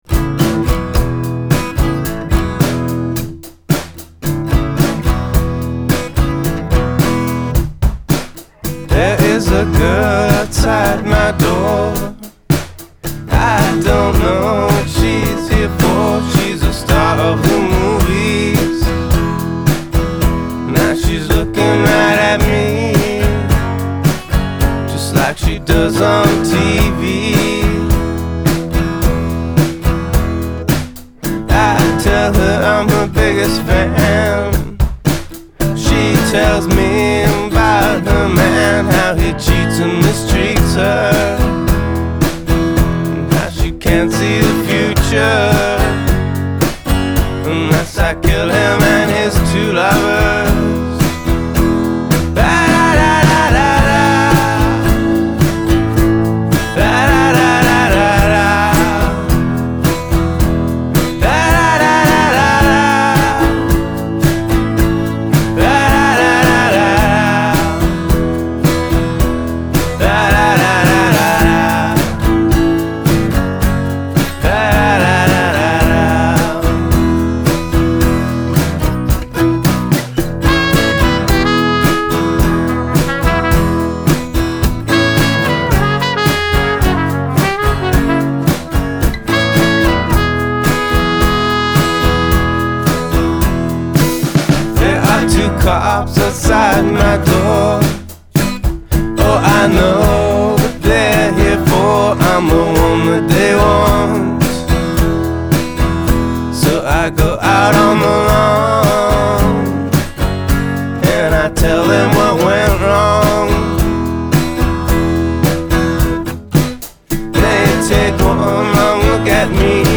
gravelly delivery
broody poprock